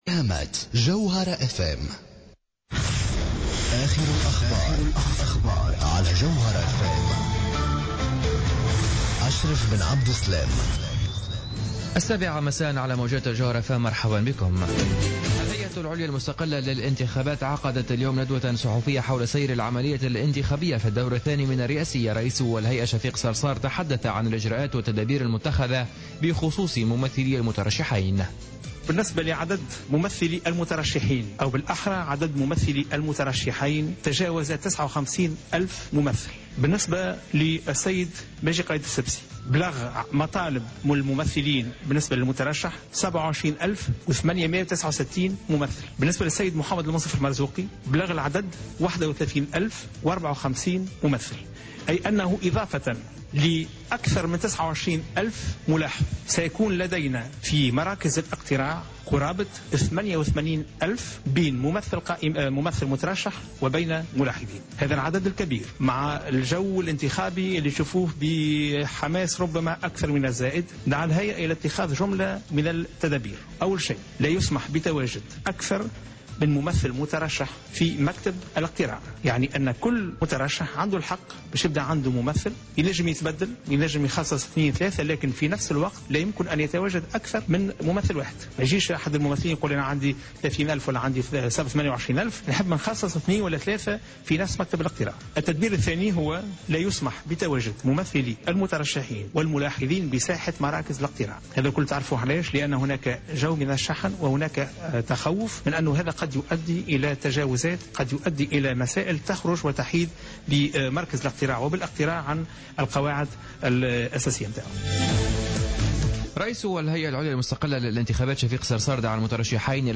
نشرة أخبار السابعة مساء ليوم الخميس 17-12-14